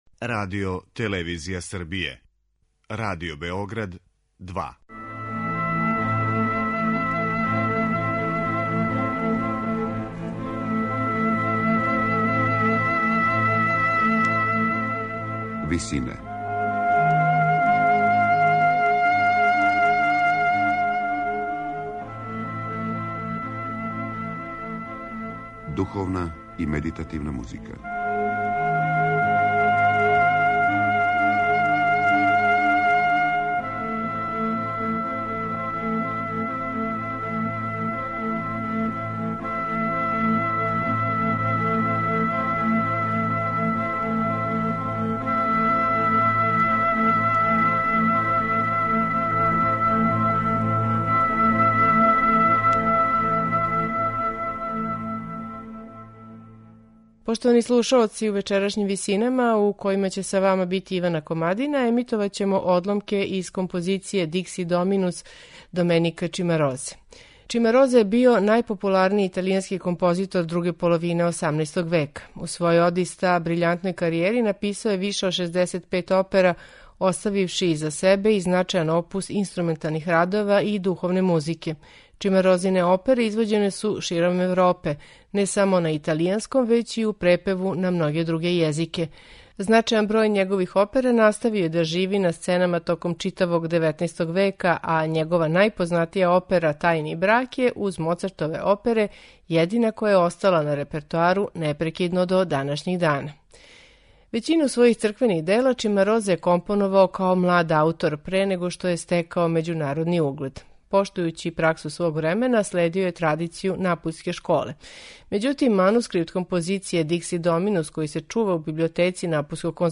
Следећи век и по дугу традицију духовне музике, аутор је овде у музичкој обради стихова користио наизменично низање солистичких и хорских одсека, као и обједињавање целокупног извођачког састава у уводном и завршном ставу.
сопран
мецо-сопран
тенор